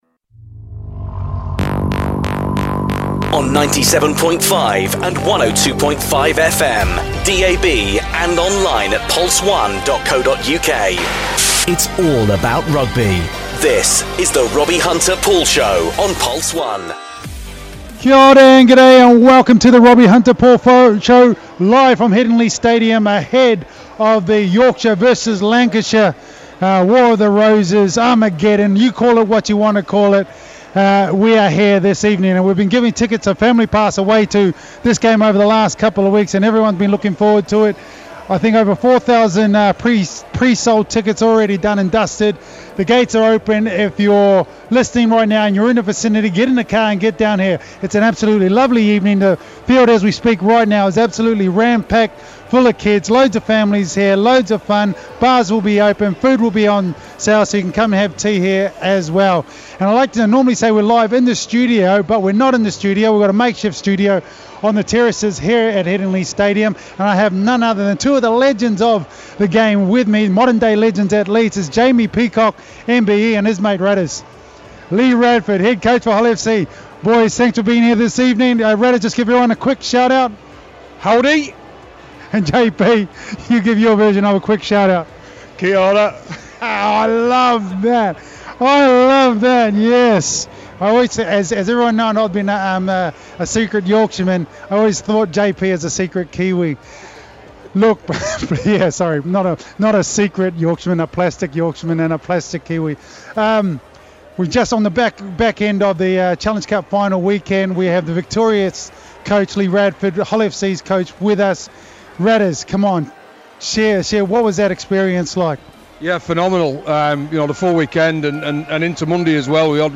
RHP Show Live from Headingley Stadium 30th August 2017